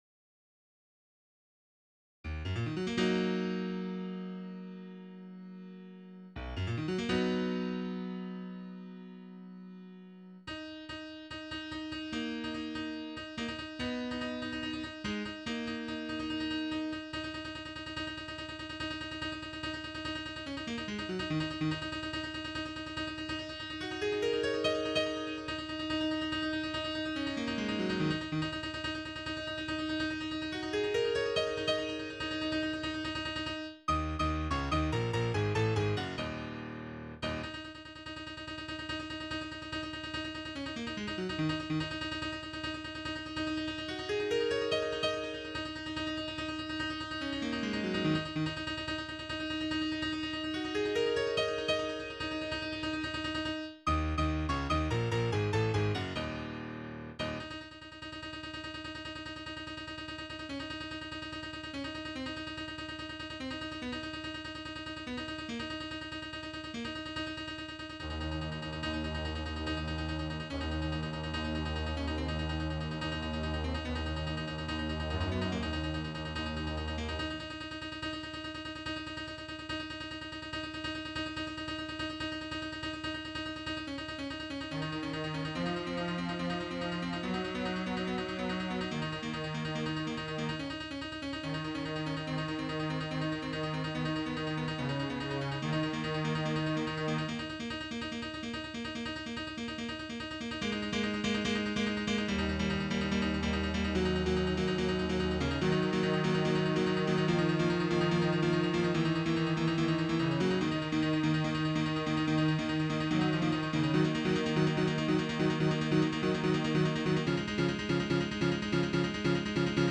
Title Skiprock Opus # 23 Year 0000 Duration 00:05:15 Self-Rating 3 Description This is definitely one of those done for the computer playback more than any real performance. The third piano staff and cello are optional. mp3 download wav download Files: mp3 wav Tags: Trio, Piano, Strings Plays: 1820 Likes: 0